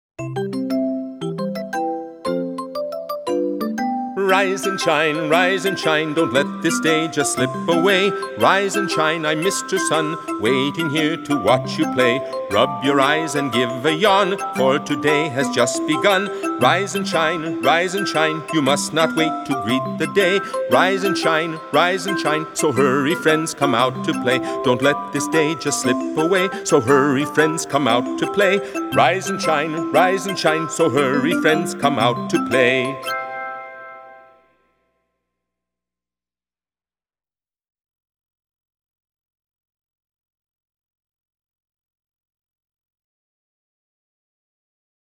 05-Rise-and-Shine-Voice.m4a